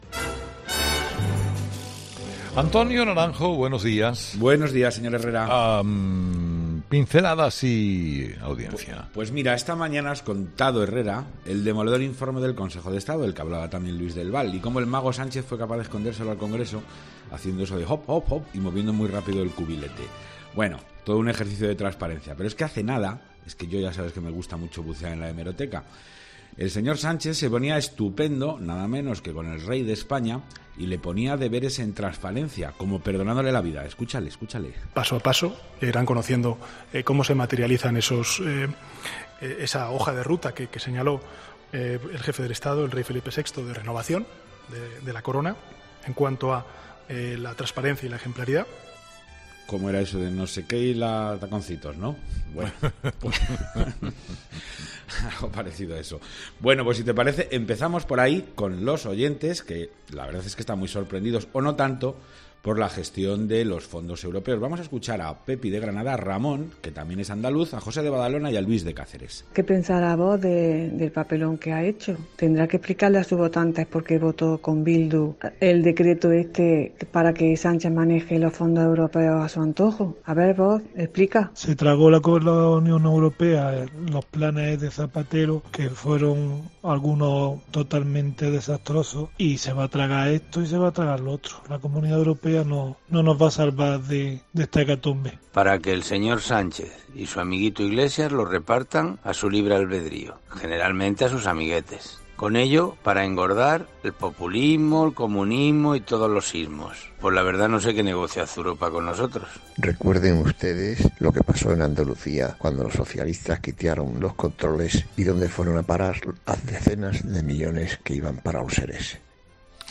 Los oyentes, de nuevo, protagonistas en 'Herrera en COPE' con su particular tertulia.